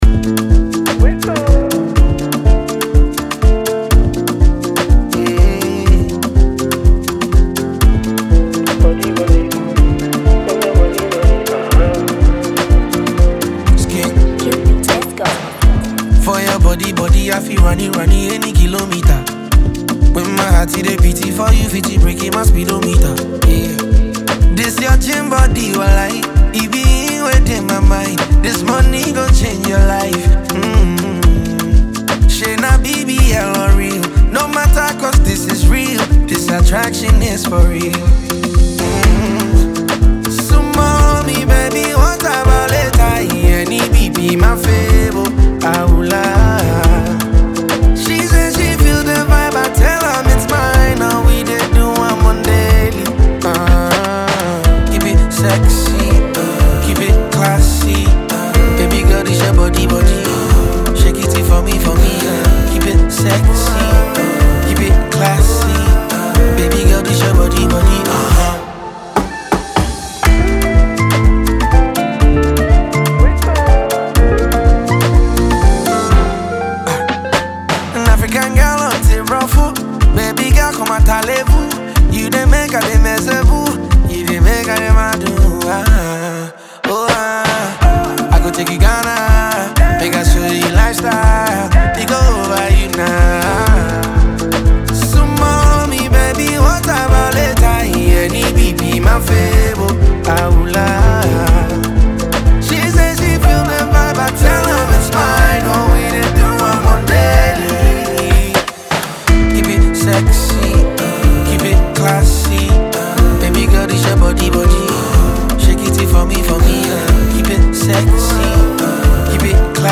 studio tune